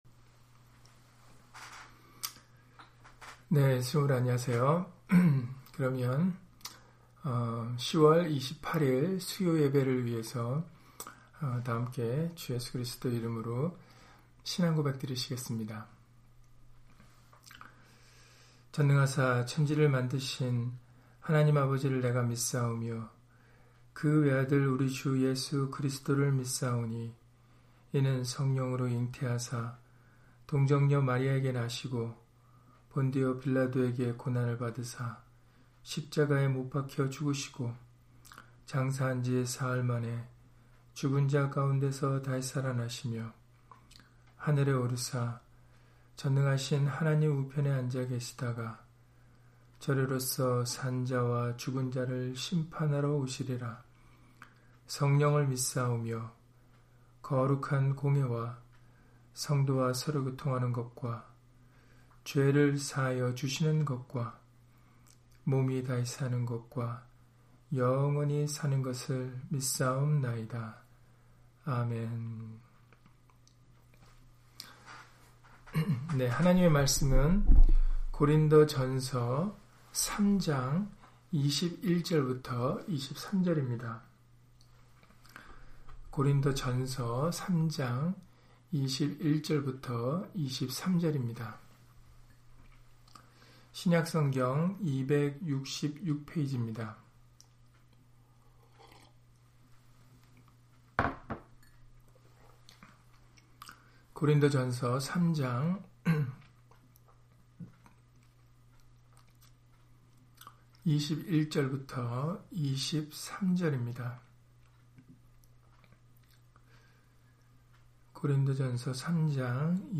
고린도전서 3장 21-23절 [누구든지 사람을 자랑하지 말라] - 주일/수요예배 설교 - 주 예수 그리스도 이름 예배당